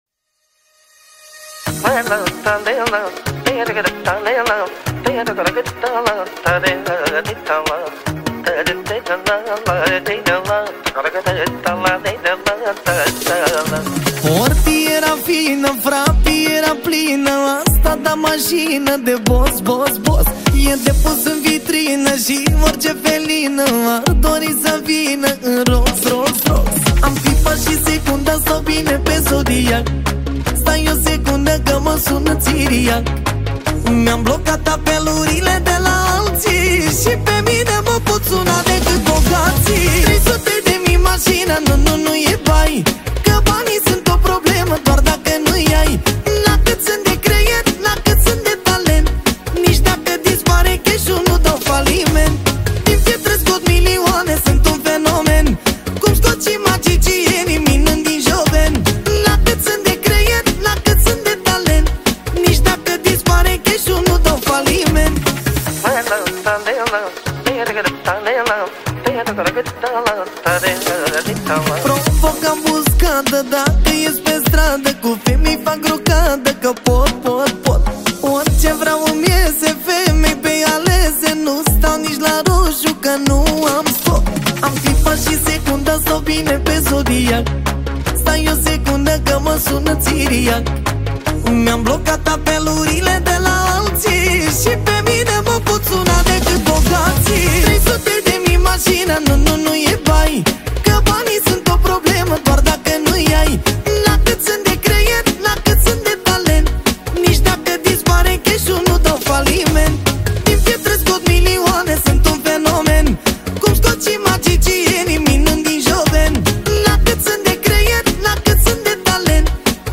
Data: 18.10.2024  Manele New-Live Hits: 0